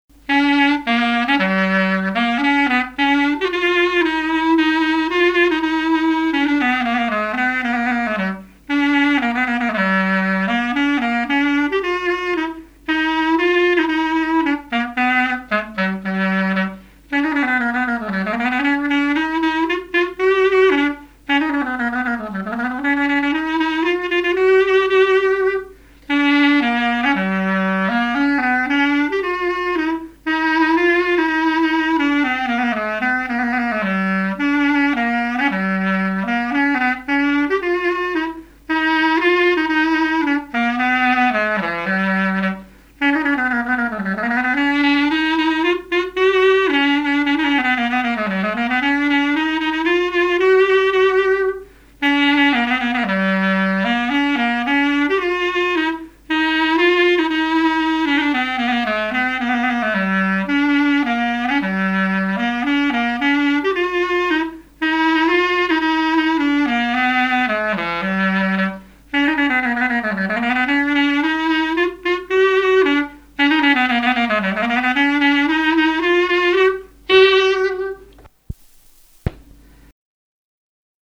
Marche nuptiale
fiançaille, noce
Pièce musicale inédite